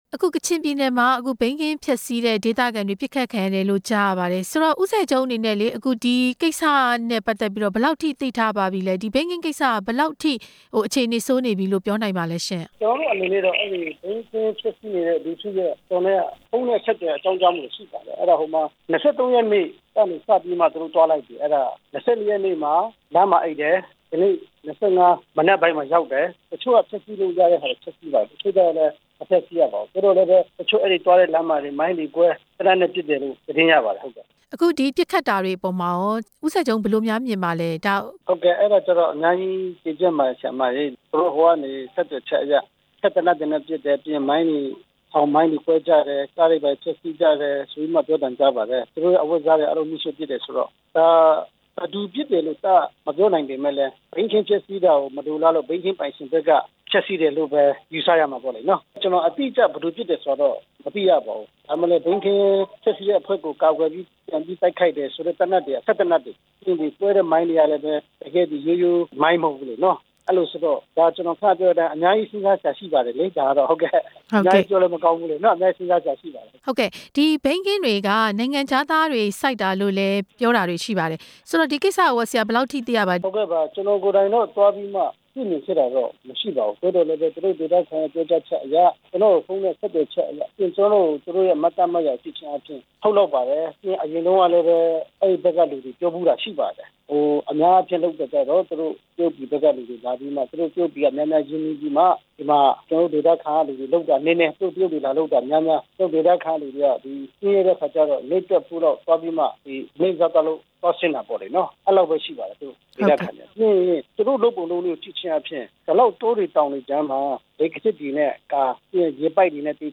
ဘိန်းခင်းဖျက်ဖို့သွားသူတွေ တိုက်ခိုက်ခံရမှု ဝိုင်းမော်အမတ်နဲ့မေးမြန်းချက်